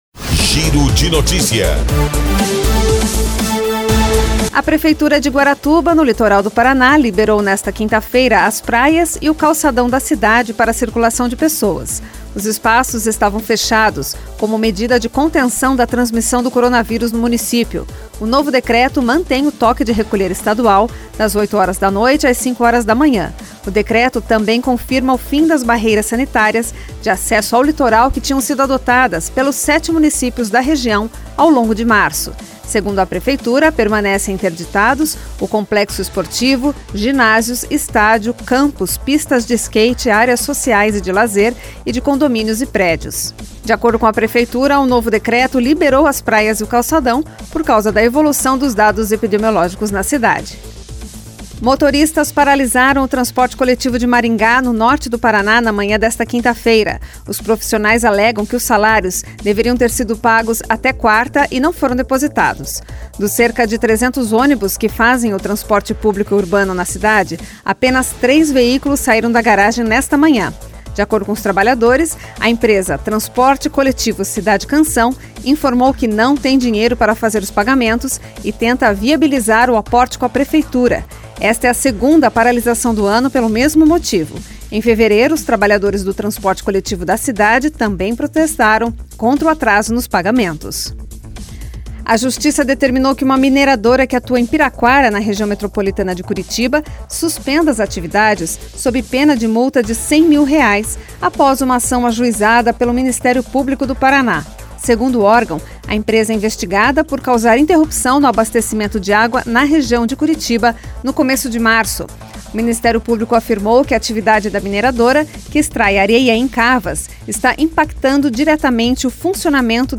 Giro de Notícias Manhã COM TRILHA